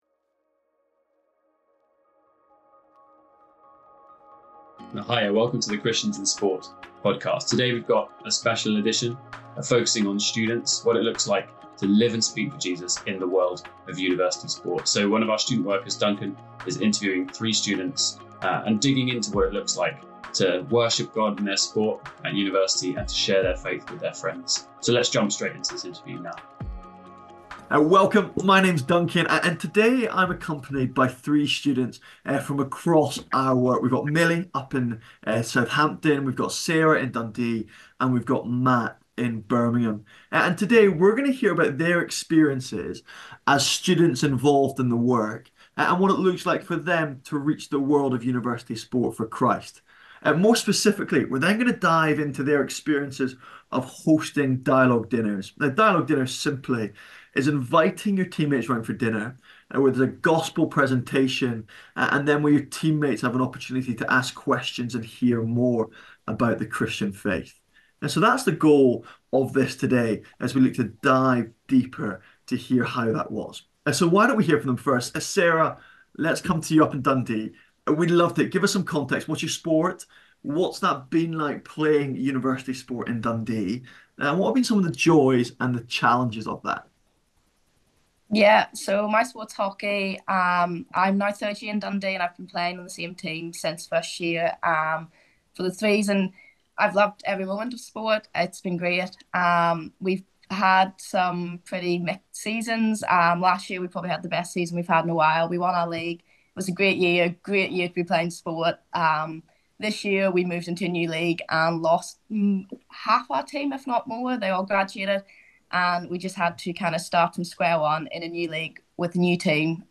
chats with three students about their experience of sharing their faith at university.